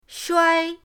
shuai1.mp3